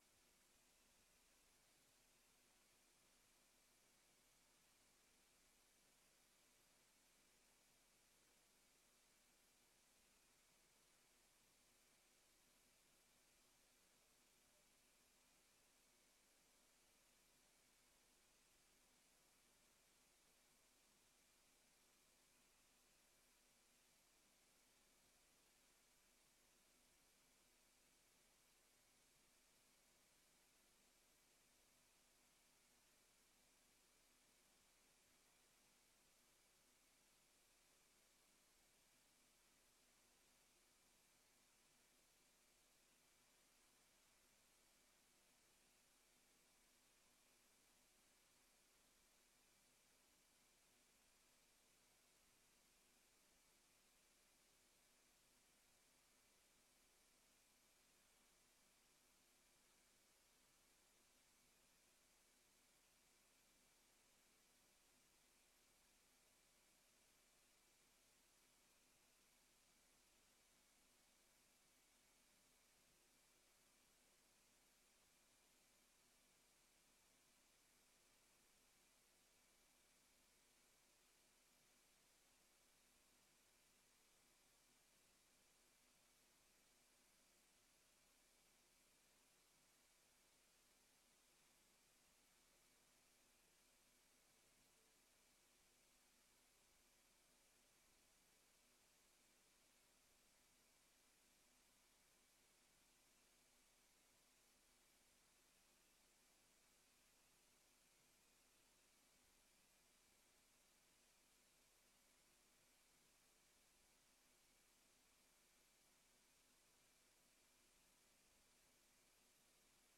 Locatie: Raadzaal
Toespraak en beëdiging door de commissaris van de Koning, Hans Oosters